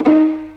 Destroy - CartoonPerc.wav